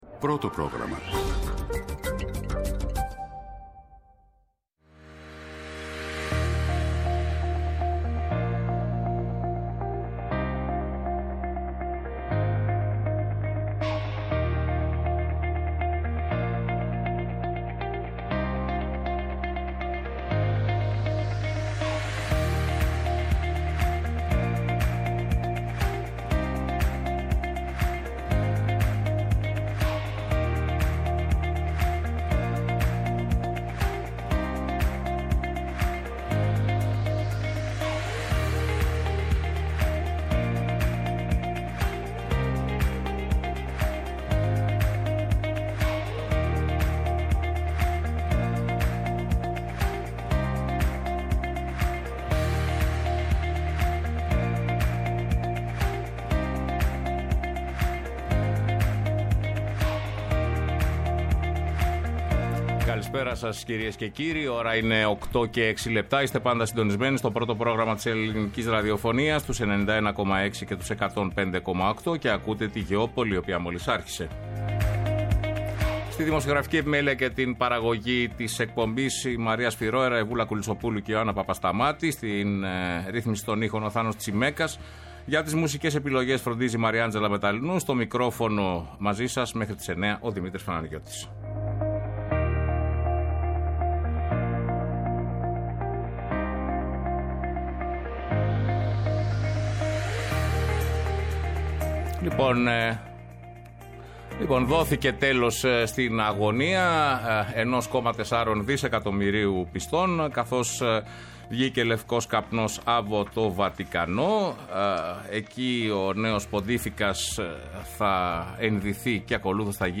Καλεσμένοι απόψε στην εκπομπή για να σχολιάσουν την εκλογή του νέου Πάπα:
Σε απευθείας σύνδεση με το Βατικανό απο το ΕΡΤnews παρακολουθούμε την ανακοίνωση της εκλογής του νέου Ποντίφηκα καθώς και το πρώτο μήνυμά του προς τους χιλιάδες πιστούς που ειναι συγκεντρωμένοι στην πλατεία του Αγίου Πέτρου.